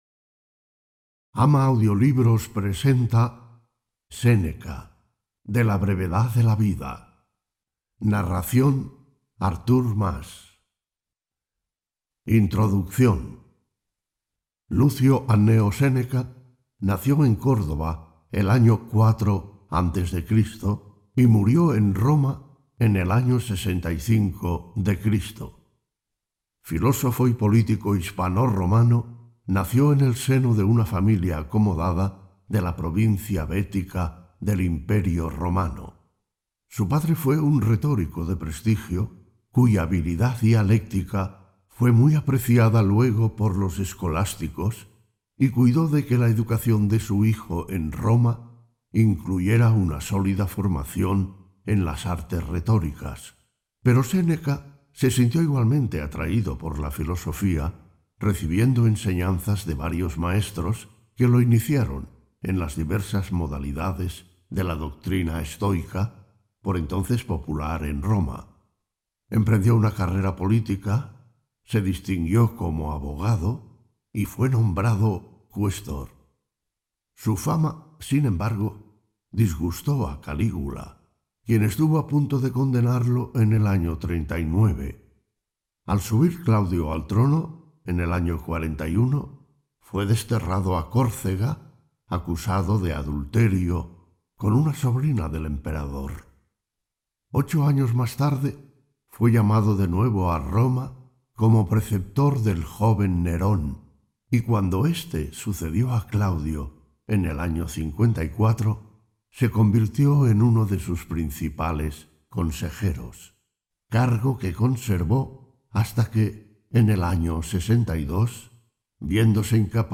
seneca-de-la-brevedad-de-la-vida-audiolibro-completo-en-espanol-voz-real-humana-vdownloader.mp3